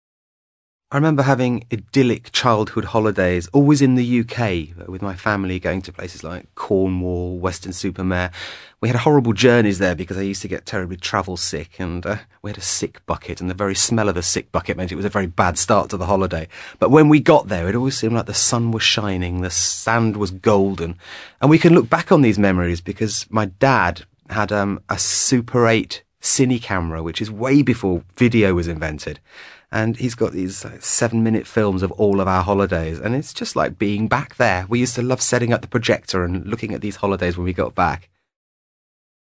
ACTIVITY 165: You will listen to two men talking about childhood holidays.
SPEAKER 1